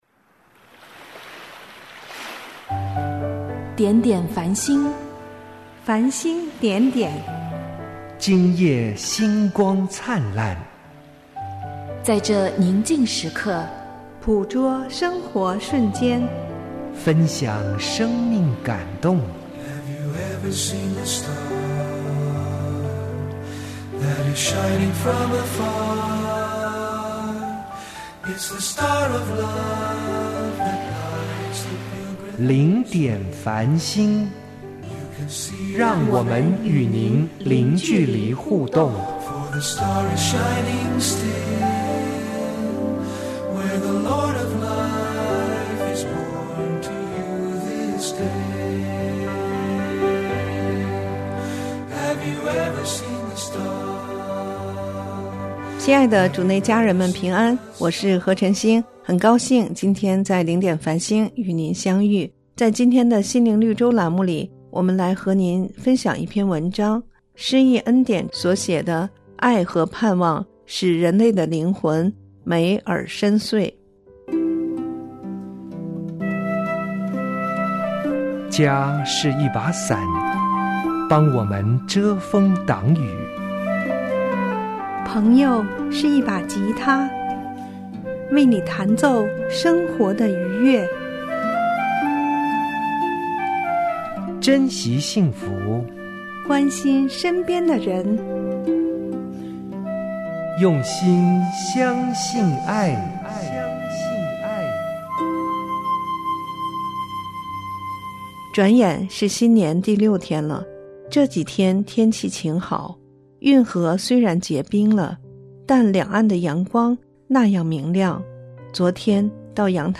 心灵绿洲：爱和盼望使人的灵魂美而深邃；配乐朗诵